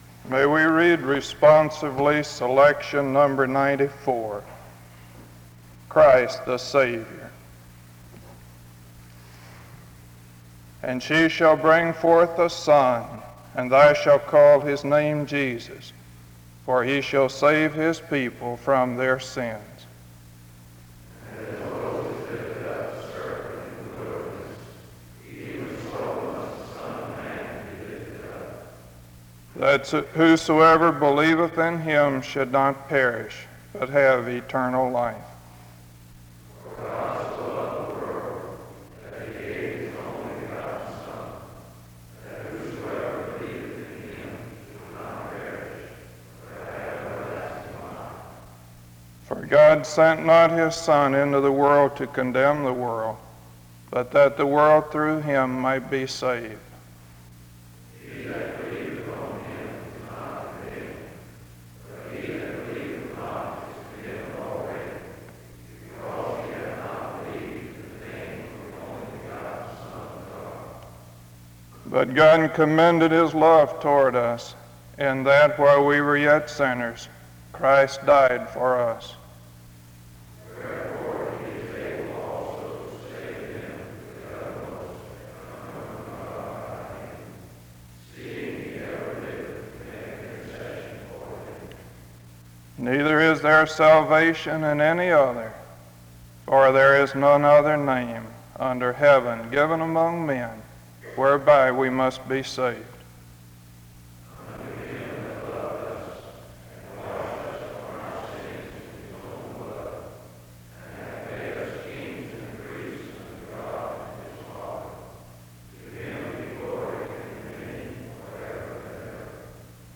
The service begins with a responsive reading from 0:00-2:02. A prayer is offered from 2:06-6:14. Music plays from 6:20-10:06.